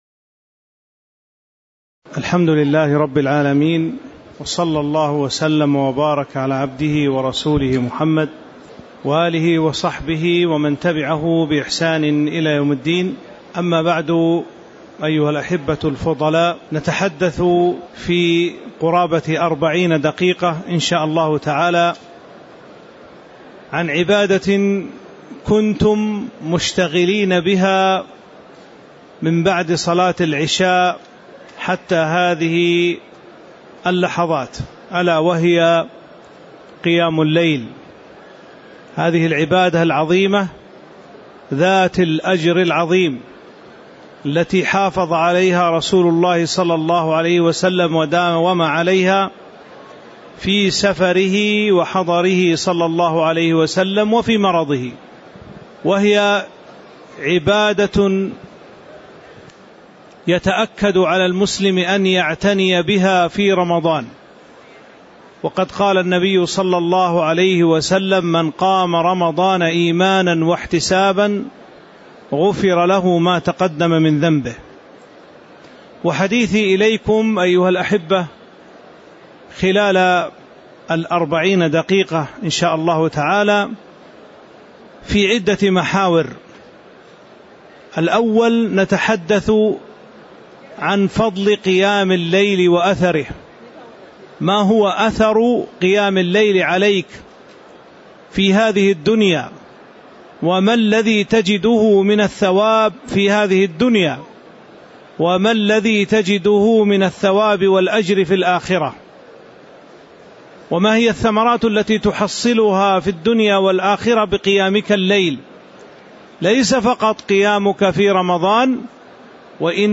تاريخ النشر ١٩ رمضان ١٤٤٠ هـ المكان: المسجد النبوي الشيخ